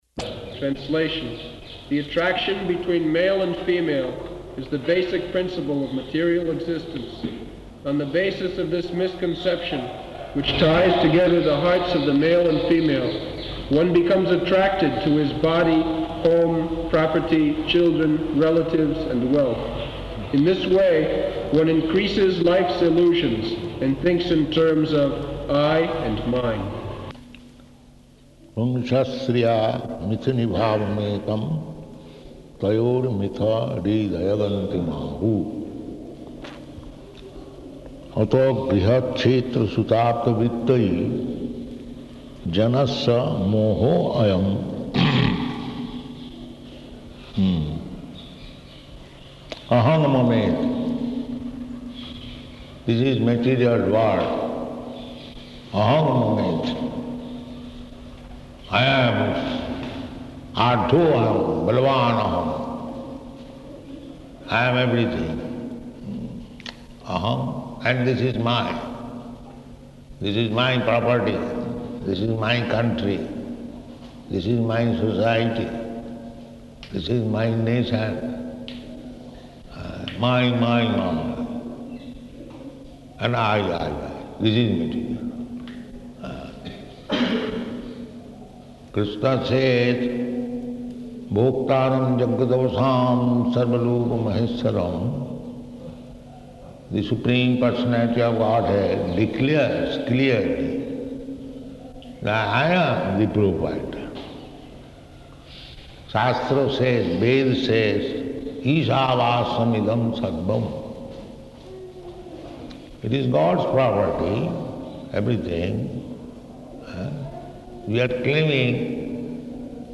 Location: Vṛndāvana